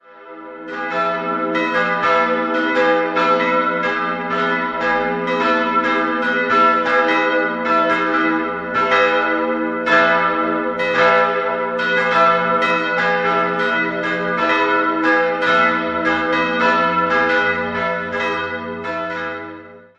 Die große Glocke wurde 1899 von der Firma Bachmair in Ingolstadt gegossen, die mittlere stammt von Christoph Taller (München) aus dem Jahr 1725 und die kleine ist ein Werk der Gießerei Hamm (Regensburg) von 1930.